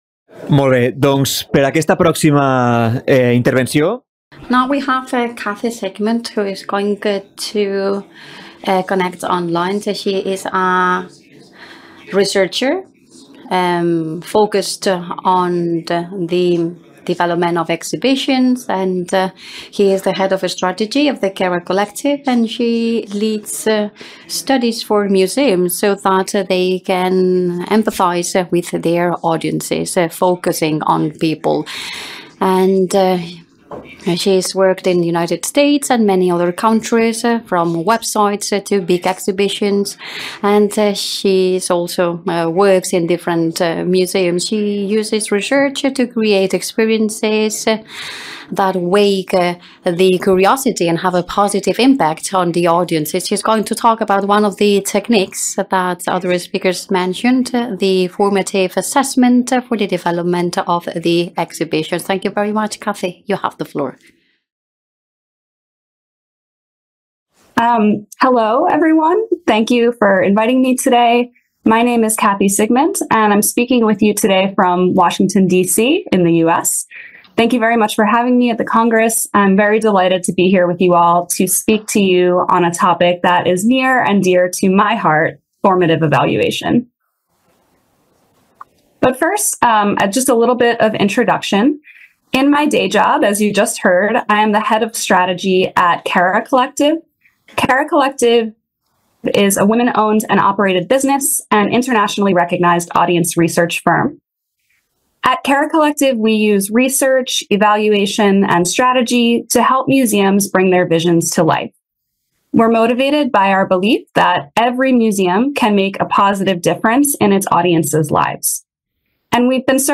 Conferència
en el marc de la 9a Jornada Observatori dels Públics del Patrimoni Cultural de Catalunya